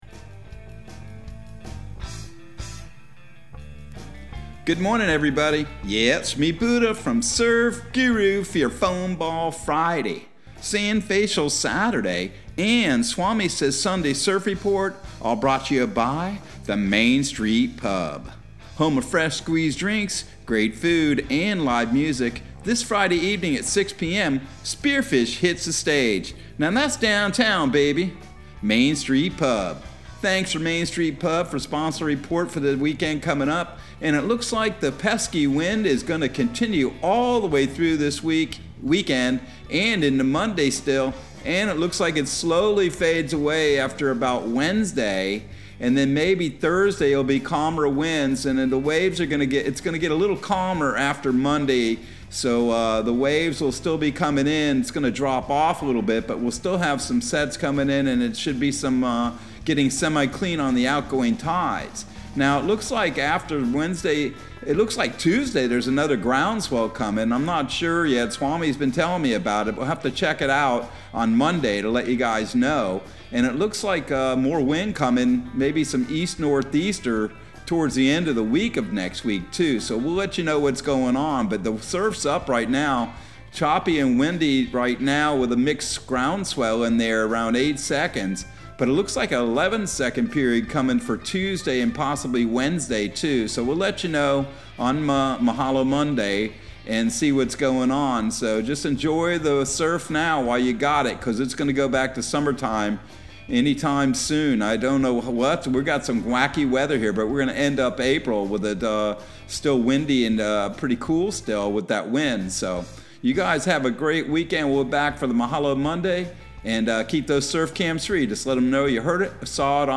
Surf Guru Surf Report and Forecast 04/22/2022 Audio surf report and surf forecast on April 22 for Central Florida and the Southeast.